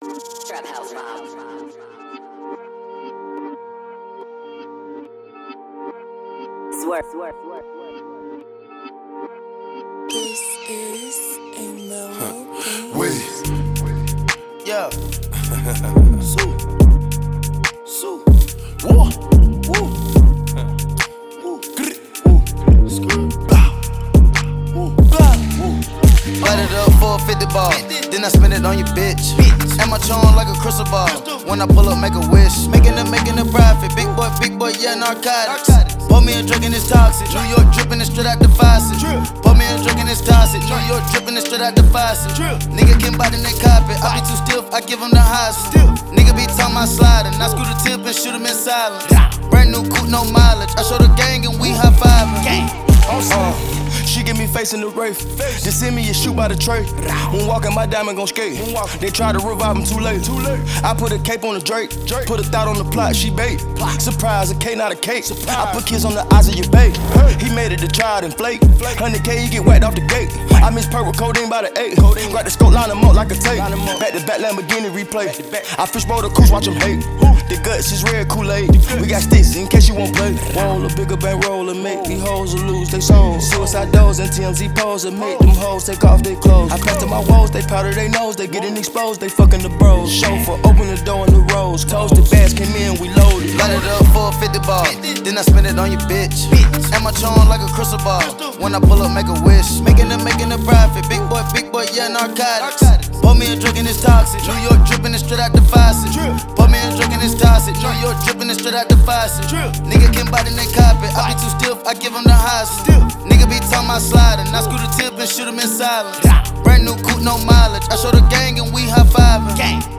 American rap trio